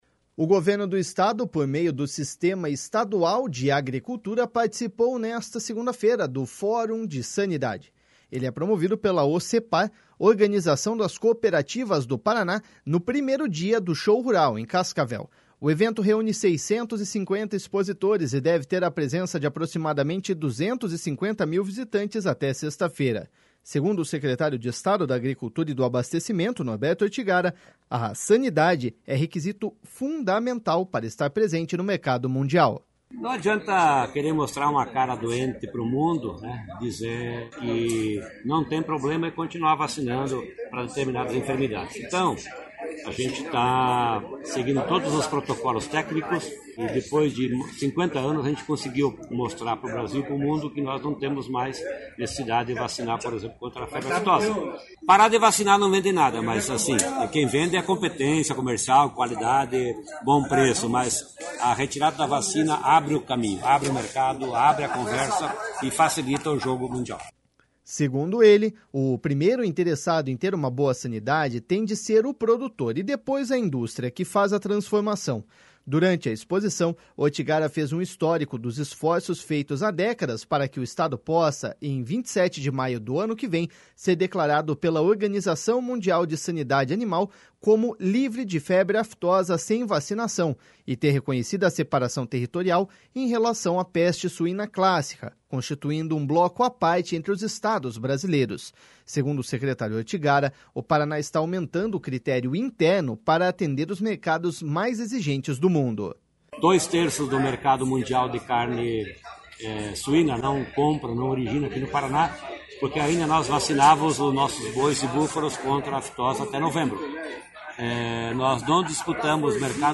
Segundo o secretário de Estado da Agricultura e do Abastecimento, Norberto Ortigara, a sanidade é requisito fundamental para estar presente no mercado mundial.// SONORA NORBERTO ORTIGARA.//